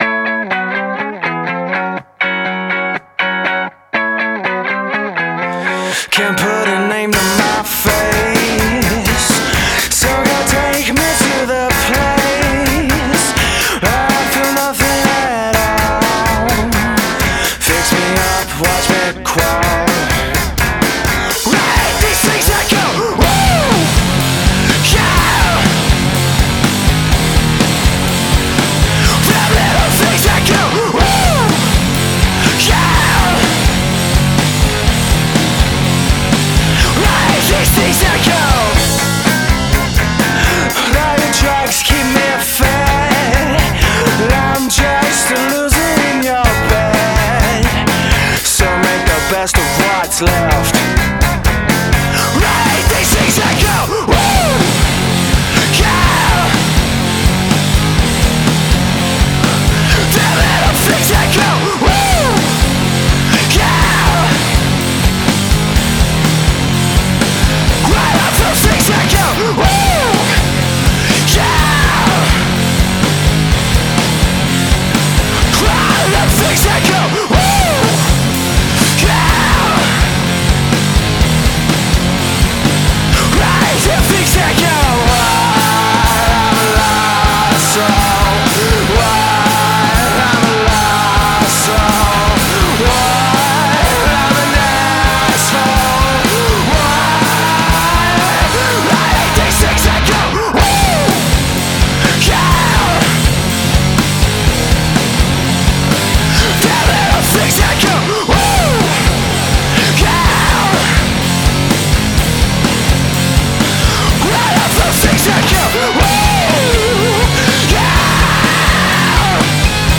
Indie Indie rock Rock